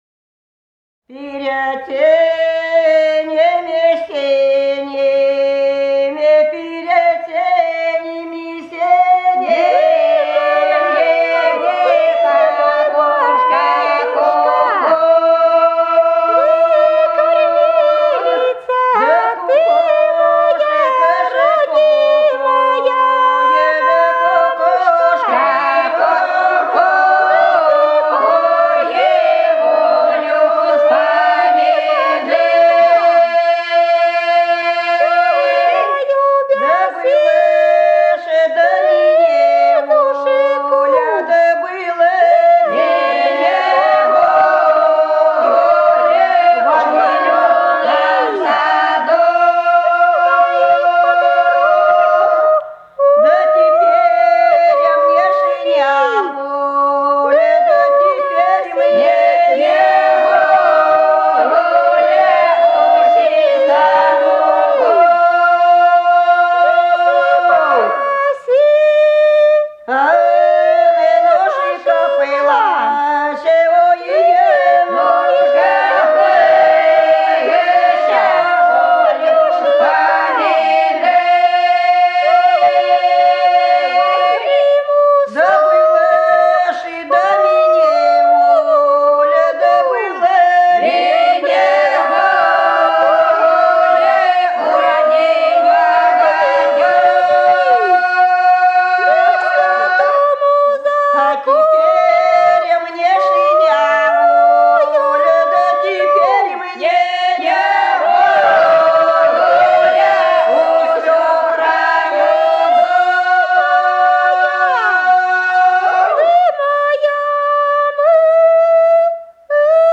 Голоса уходящего века (село Фощеватово) Перед сенями, сенями да кукушка кукуя (на девичнике)